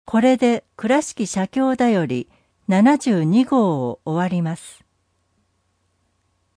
くらしき社協だより第72号 音訳版